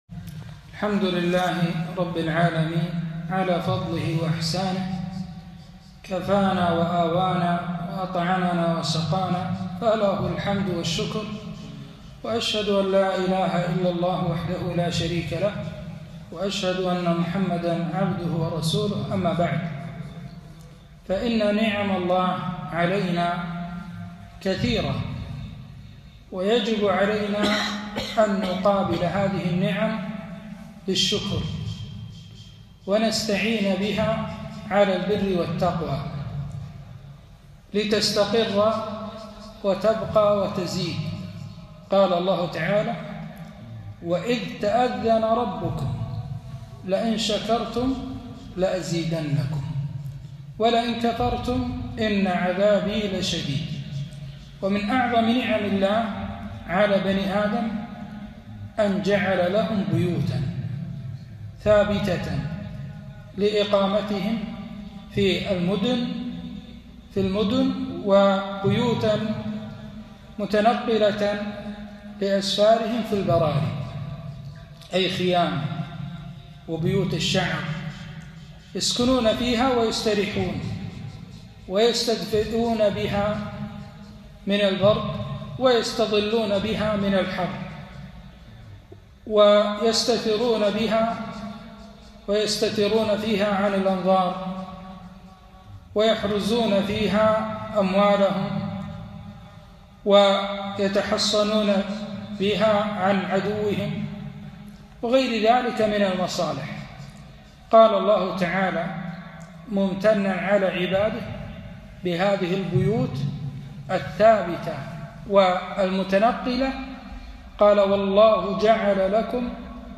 كلمة - الحمدالله على نعمة المسكن - دروس الكويت